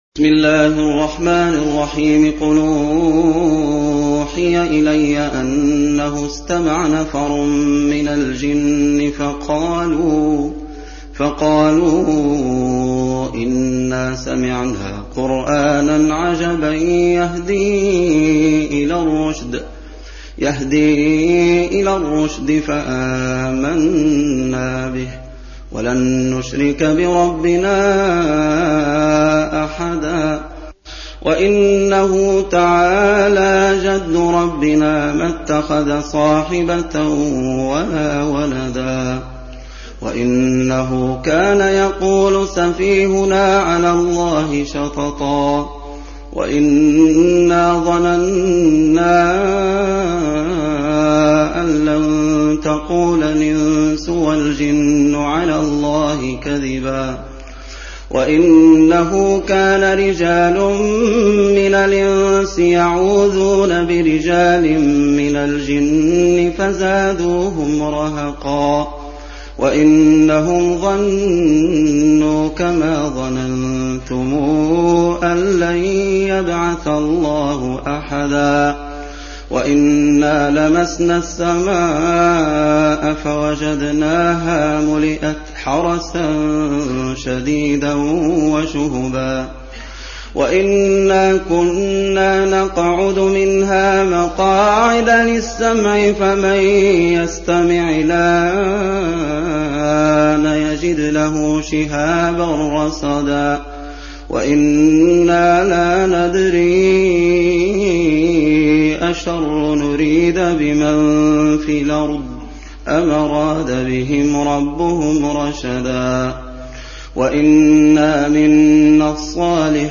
Surah Sequence تتابع السورة Download Surah حمّل السورة Reciting Murattalah Audio for 72. Surah Al-Jinn سورة الجن N.B *Surah Includes Al-Basmalah Reciters Sequents تتابع التلاوات Reciters Repeats تكرار التلاوات